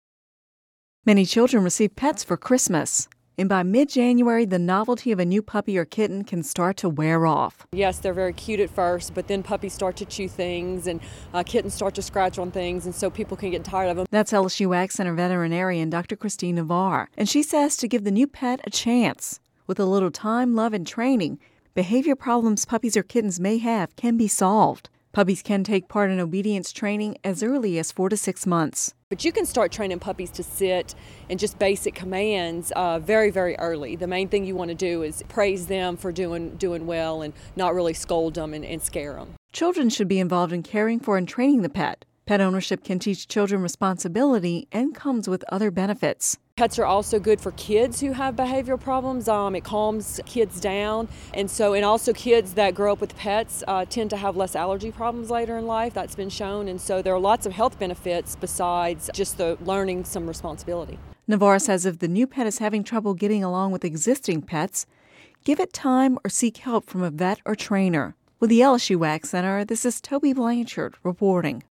(Radio News 01/17/11) Many children receive pets for Christmas and by mid-January the novelty of a new puppy or kitten can start to wear off.